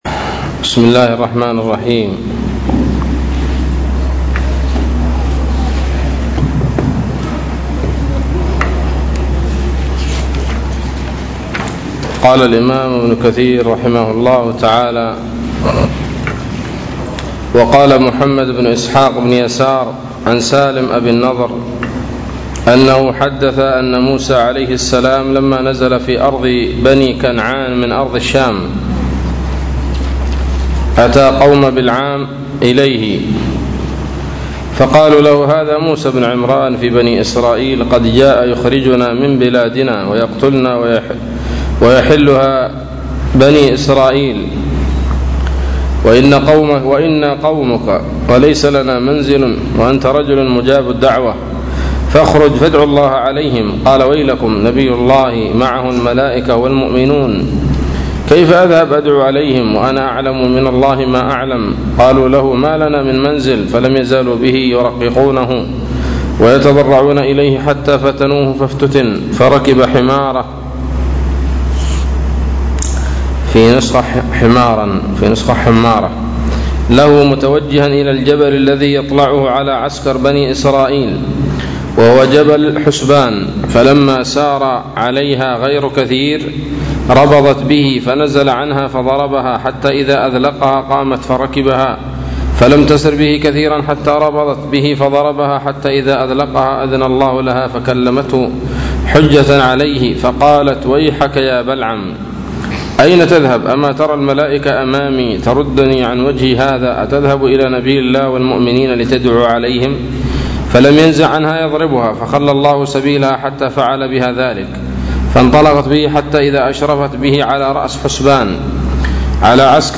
الدرس السادس والستون من سورة الأعراف من تفسير ابن كثير رحمه الله تعالى